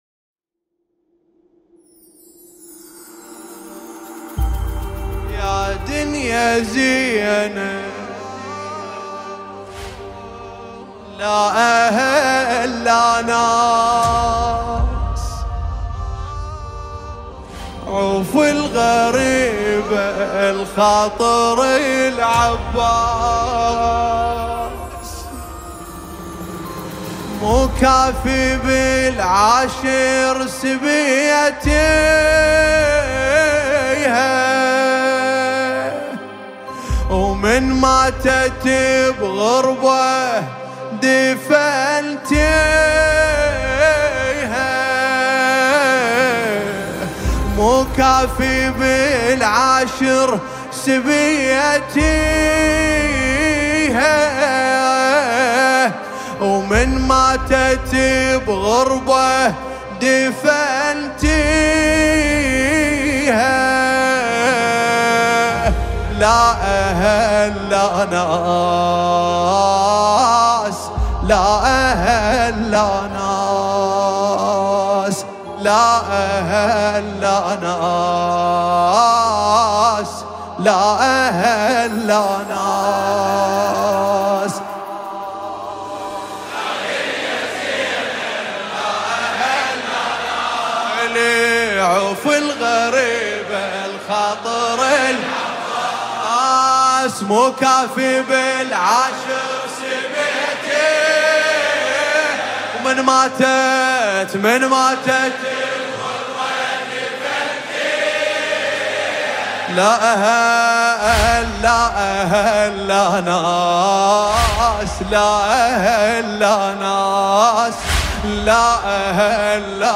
مداحی عربی